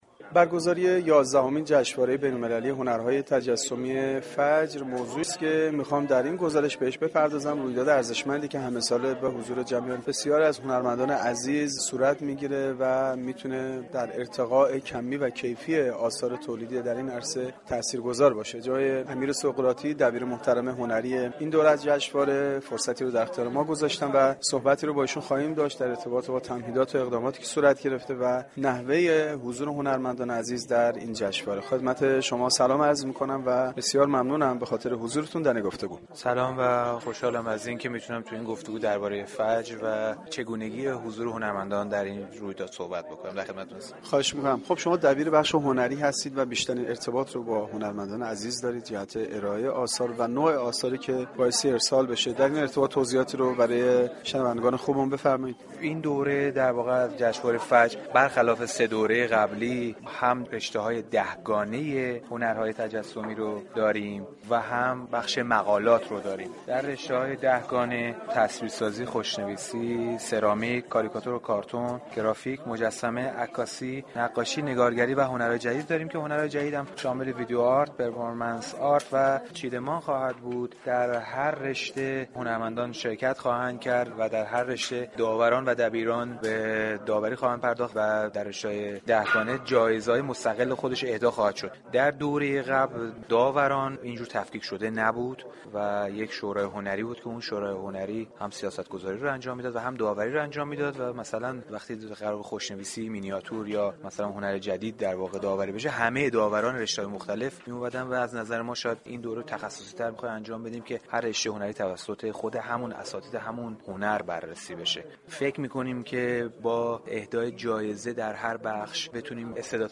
در گفتگو با گزارشگر رادیو فرهنگ